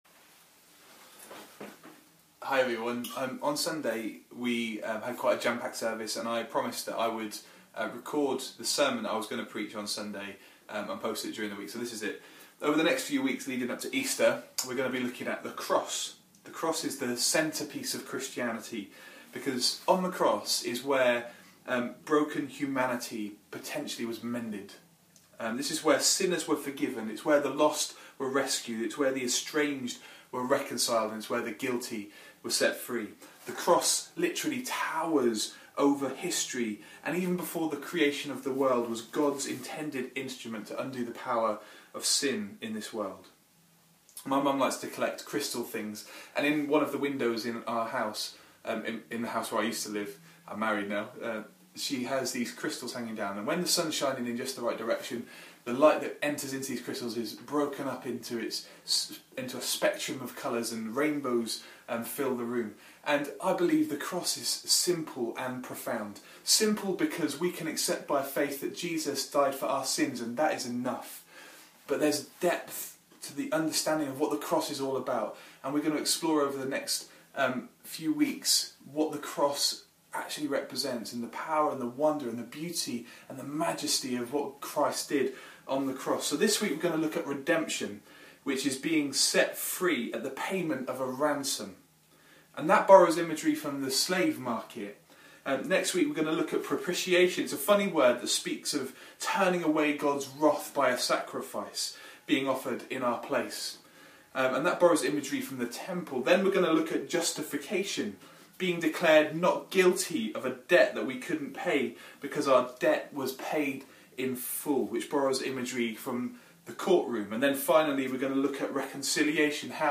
Mar 10, 2015 The Cross – Redemption (Hope South) MP3 SUBSCRIBE on iTunes(Podcast) Notes Sermons in this Series The first in our series looking at the wonder of the cross, specifically our Redemption - how Christ purchased our freedom by paying a ransom, His blood shed on the cross.